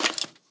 sounds / mob / skeleton / step2.ogg